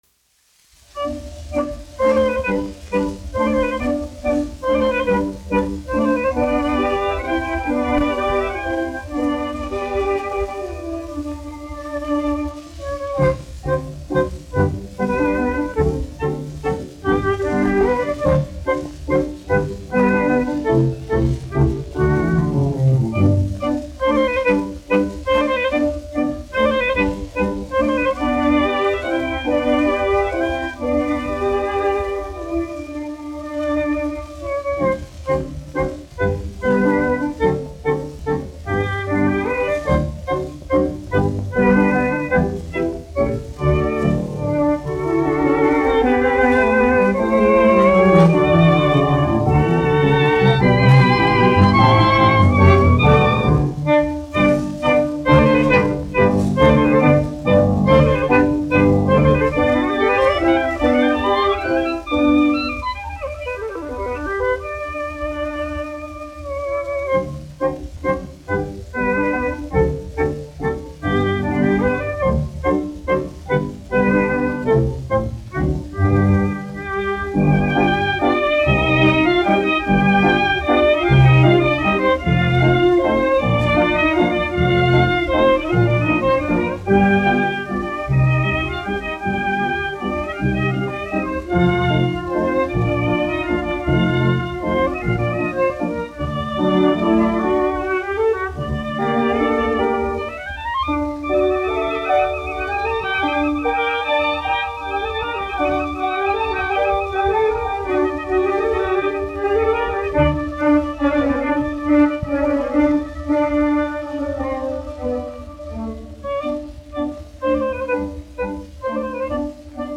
1 skpl. : analogs, 78 apgr/min, mono ; 25 cm
Menueti
Orķestra mūzika
Latvijas vēsturiskie šellaka skaņuplašu ieraksti (Kolekcija)